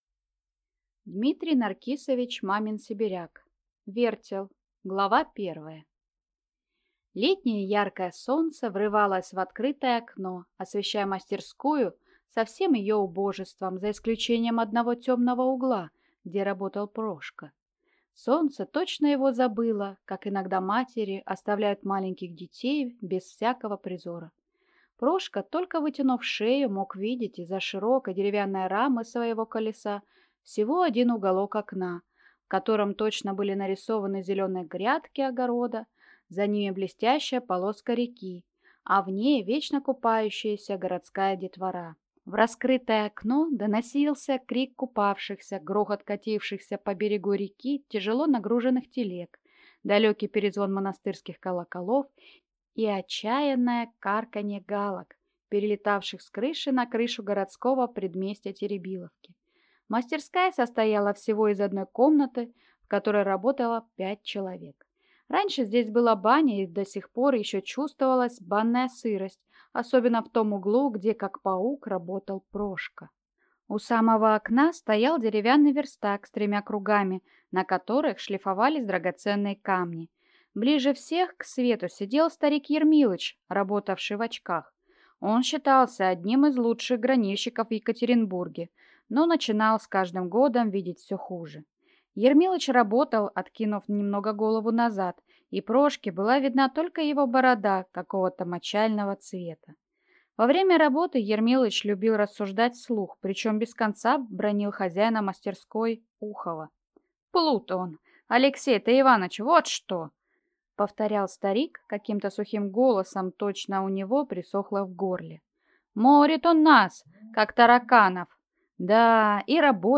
Аудиокнига Вертел | Библиотека аудиокниг